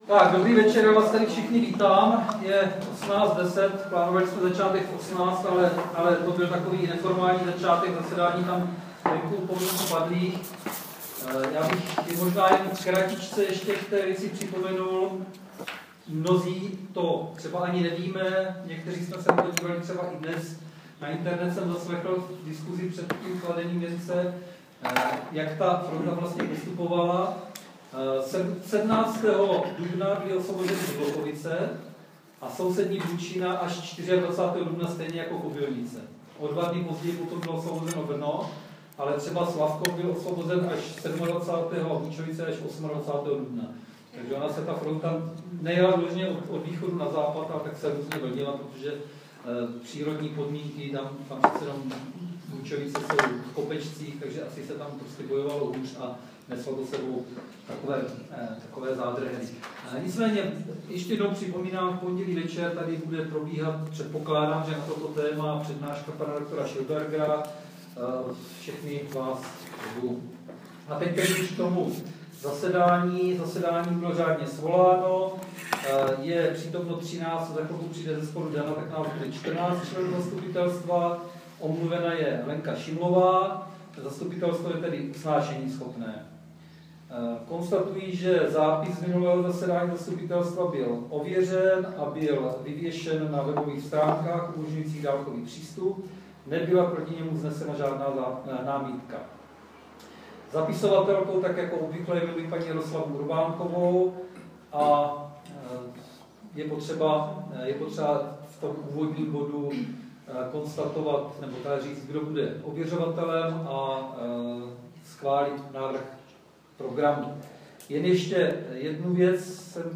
Záznam 4. zasedání zastupitelstva obce Kobylnice 23.4.2015